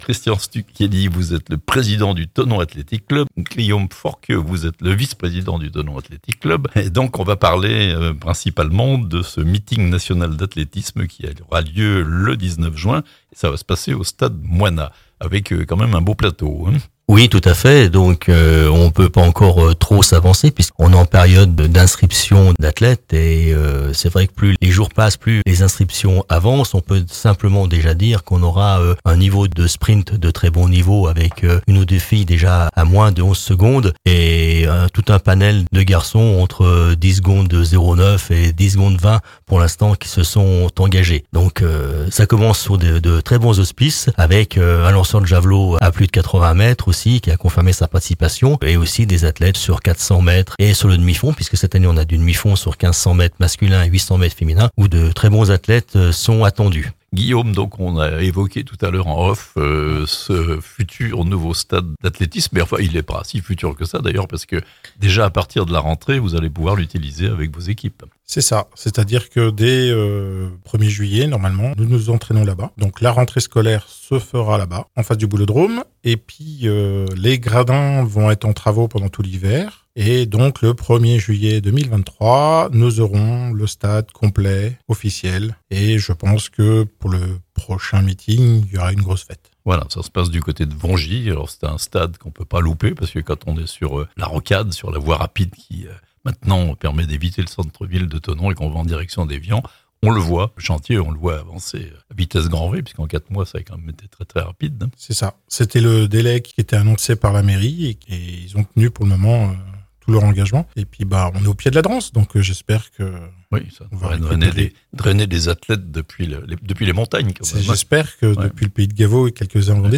Un meeting national d'athlétisme à Thonon le 19 juin (interviews)